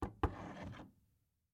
Звуки шкафа
Взяли вещь с полки шкафа